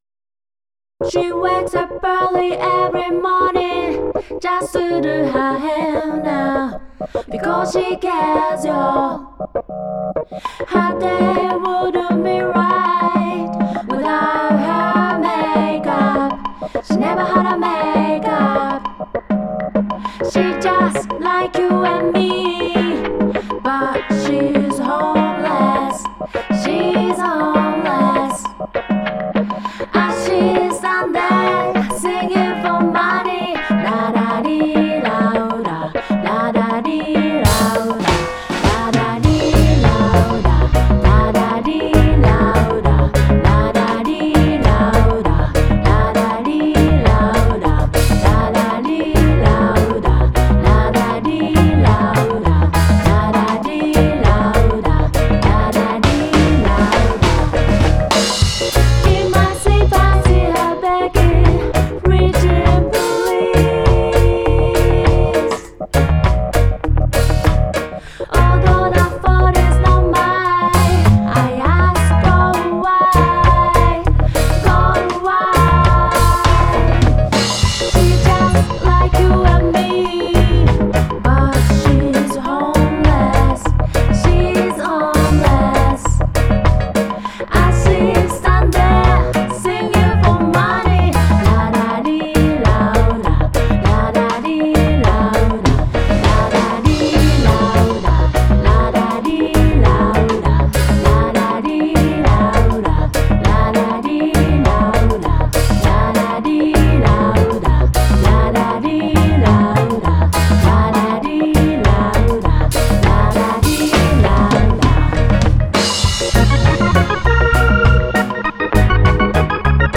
ジャンル(スタイル) JAPANESE REGGAE